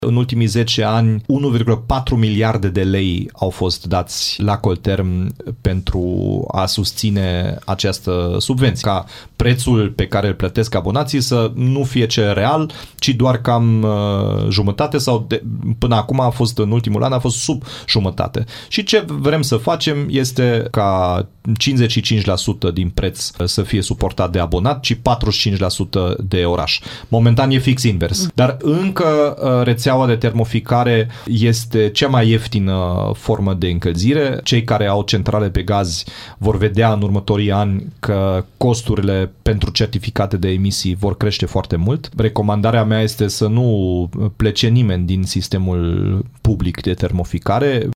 Primarul Dominic Fritz a declarat, la Radio Timișoara, că menține propunerea de a reduce subvenția pe care municipalitatea o plătește Colterm pentru termoficare.